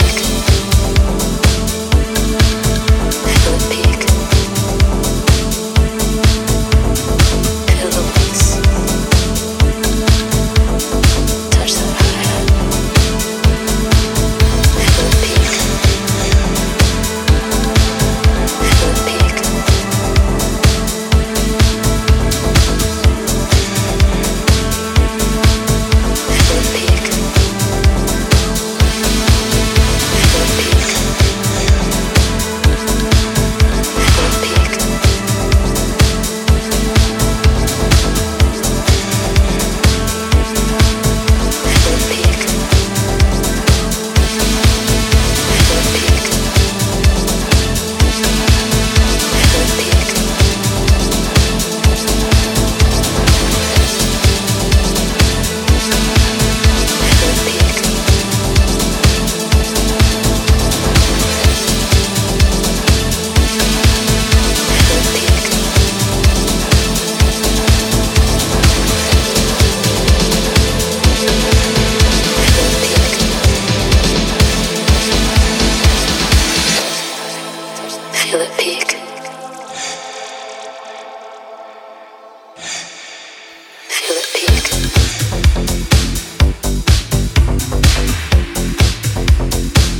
鮮やかな煌めき感や眩い陽光感を纏いながら開放感溢れるバレアリック・ディスコ〜プロト・ハウスを繰り広げていった
80's〜90'sフィーリングをセンス良く現代的に取り入れながらフロアを熱いエナジーで満たしていく大推薦盤です！